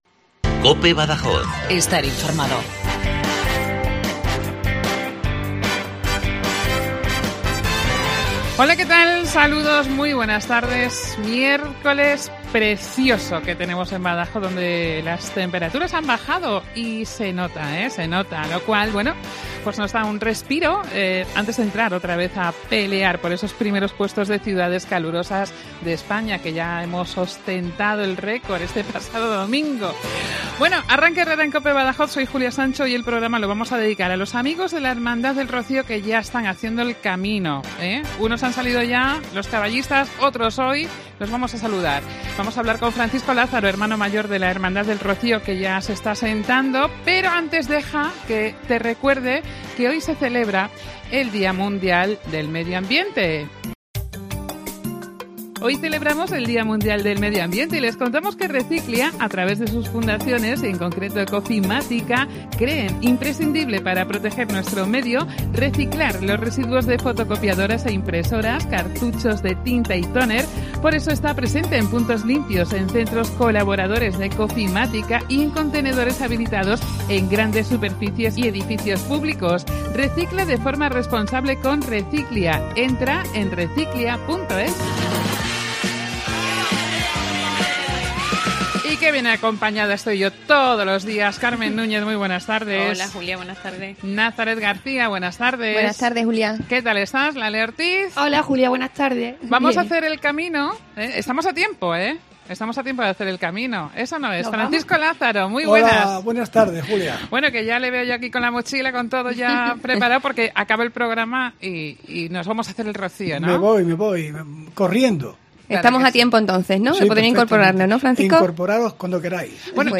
nos ha contado todo poniendo el corazón y al borde de la lágrima.